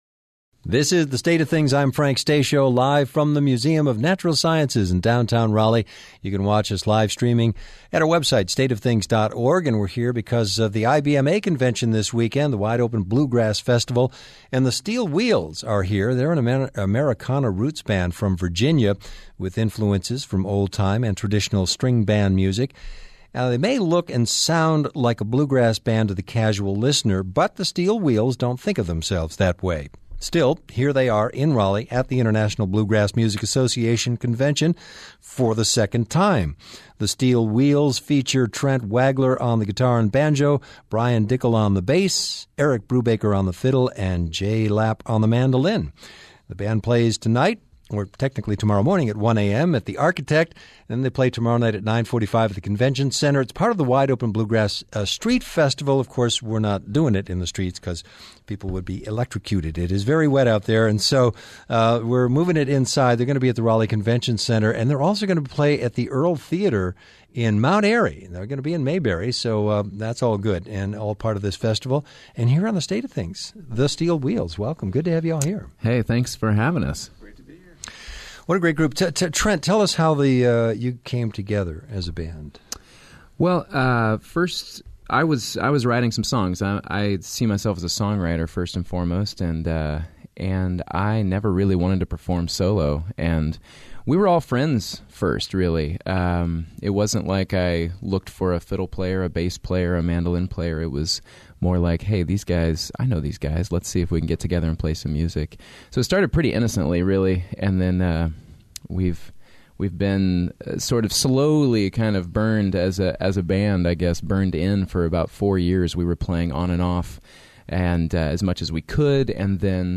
plays live
Americana roots band
guitar
bass
fiddle
mandolin